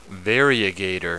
Prononciation du mot variegator en anglais (fichier audio)
Prononciation du mot : variegator